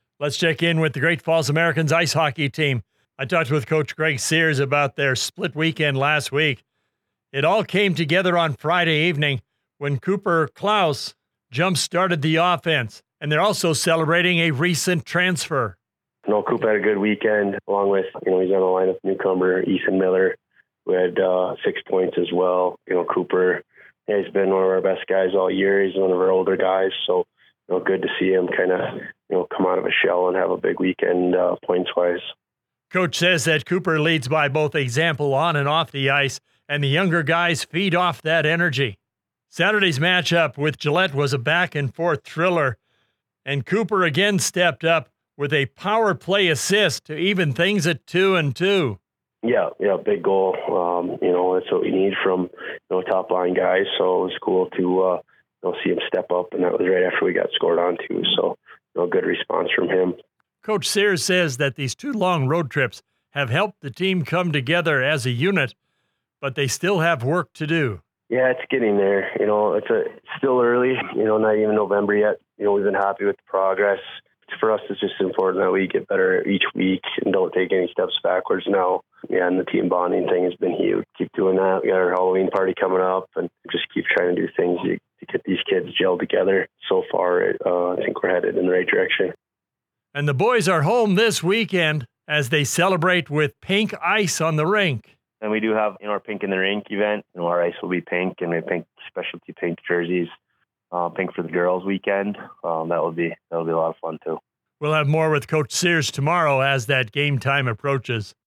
The Weekly Radio Interview on 560AM KMON in Great Falls can be heard every Wednesday, Thursday and Friday mornings during the Hourly Sports Report (6:15AM (MST), 7:15AM (MST), and 8:15AM (MST) live.